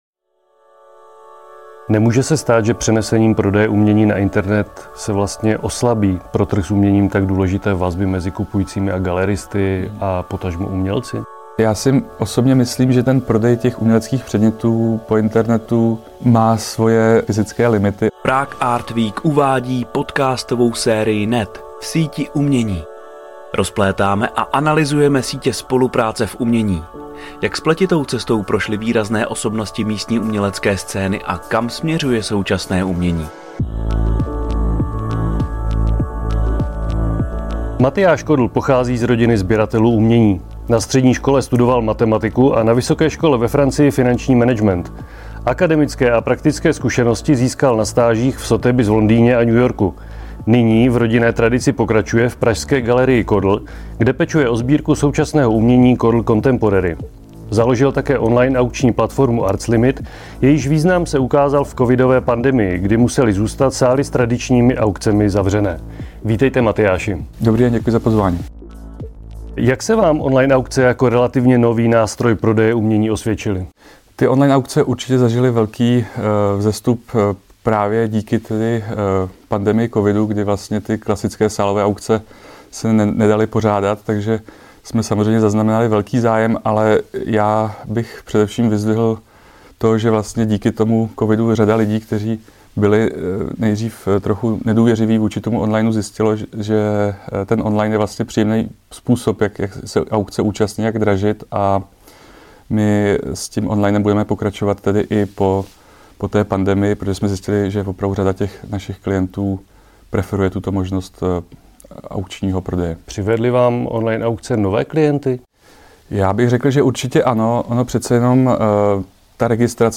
V konverzační podcastové sérii NET rozplétáme a analyzujeme sítě spolupráce v umění.